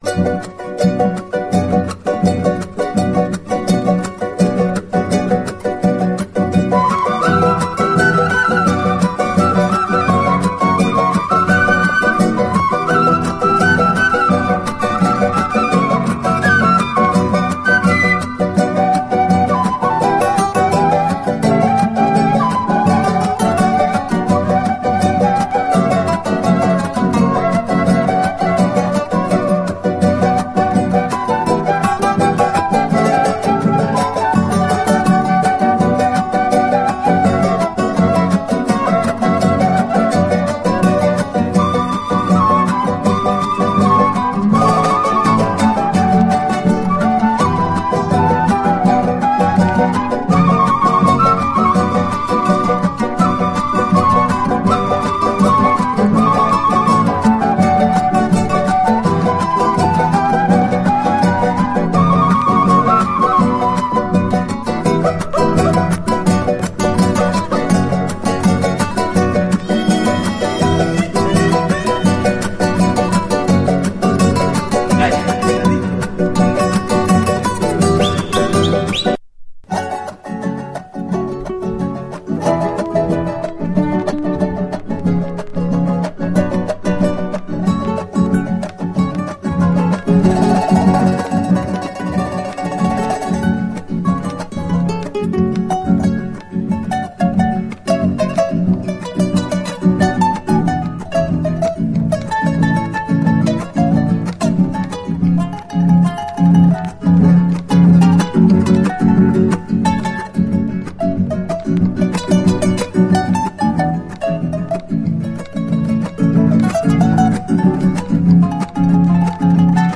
ハッピー・カリビアン
ファンク・クラシック
後半のメロウ・パートがヤバい